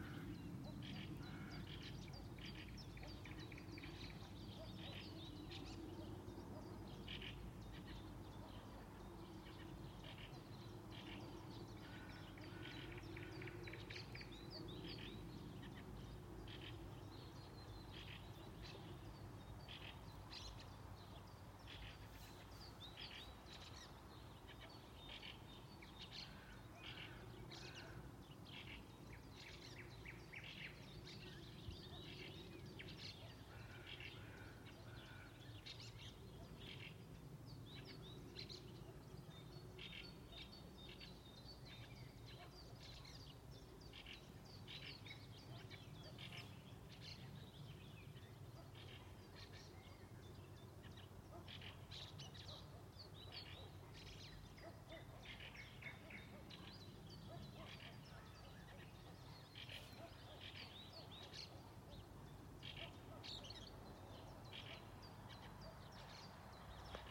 Birds -> Thrushes -> 1
Fieldfare, Turdus pilaris
Notes Vai tā čirkst pelēkais strazds, vai varbūt tas ir kāds cits manas upmalas iemītnieks?